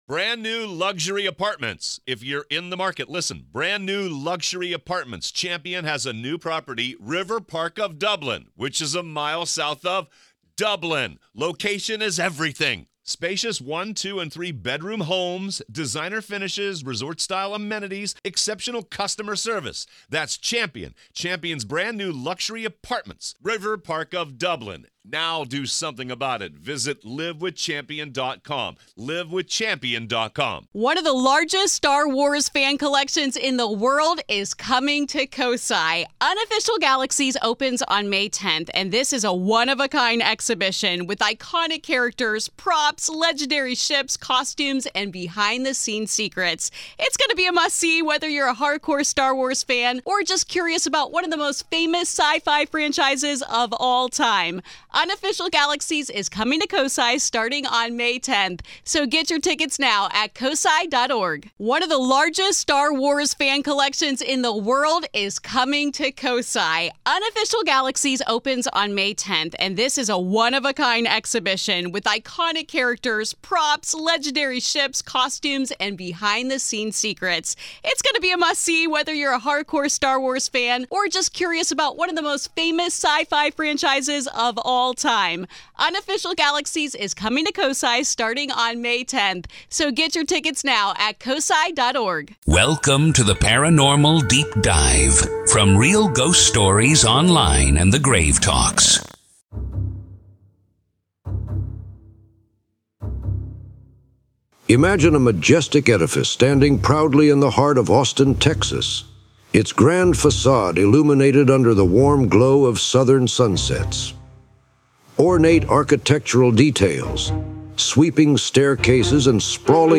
In this episode, we delve deep into the rich history of the Driskill Hotel, exploring its origins, architectural splendor, and the pivotal events that have shaped its legacy. Through compelling storytelling and expert interviews, we uncover the chilling tales of restless spirits said to roam its corridors.